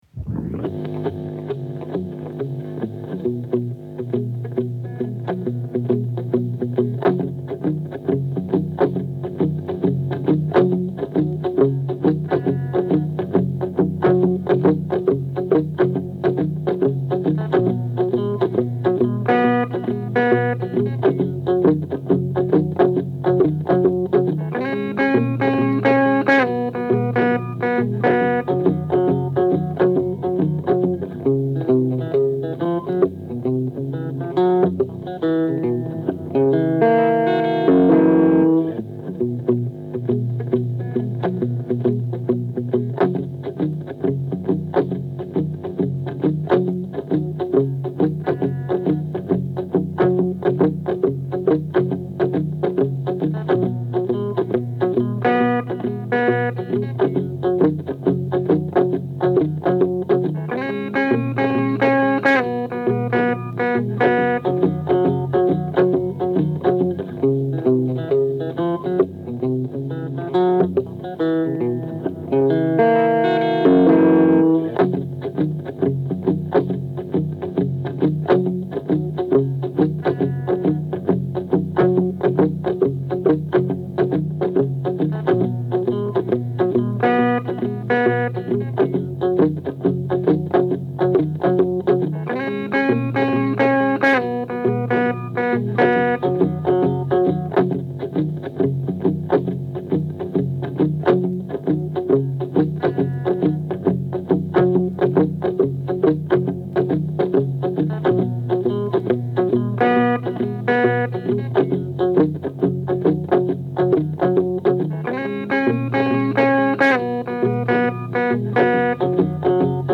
Inefable, incoherente.